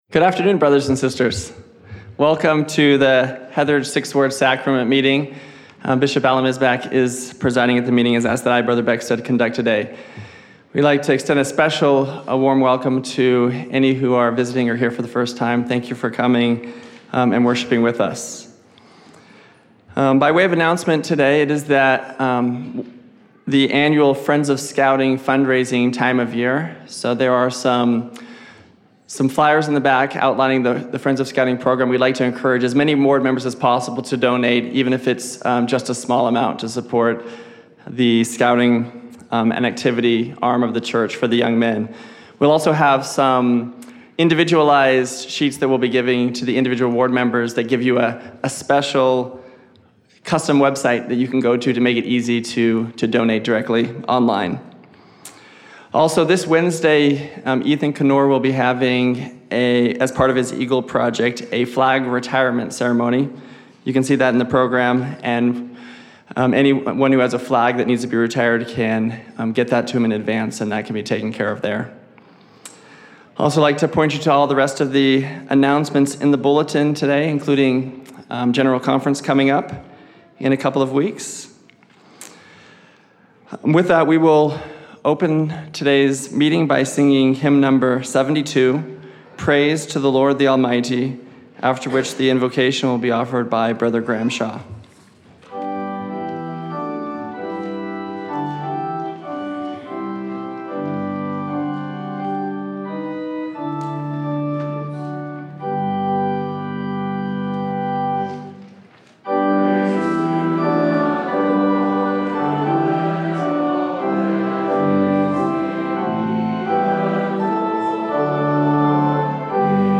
Heatheridge 6th Ward Sacrament Meeting
Heatheridge 6th Ward Sacrament Meeting 09/11/16 (Ward Conference)